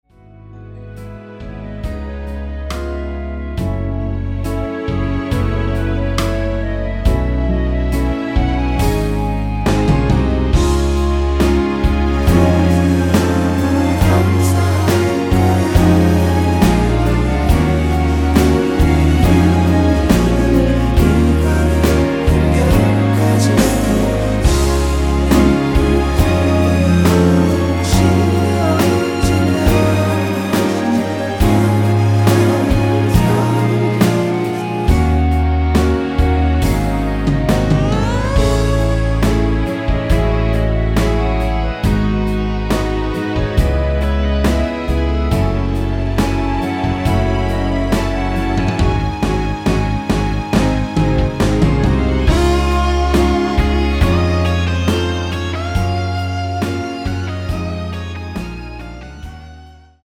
원키 멜로디와 코러스 포함된 MR입니다.(미리듣기 확인)
앞부분30초, 뒷부분30초씩 편집해서 올려 드리고 있습니다.
중간에 음이 끈어지고 다시 나오는 이유는